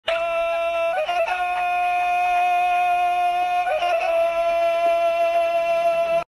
Tarzan’s Yell (the Legend Of Tarzan)